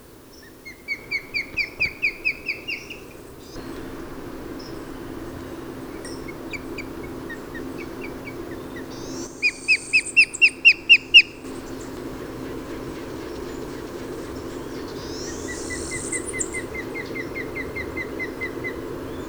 Pandion haliaetus
aguila-de-mar.wav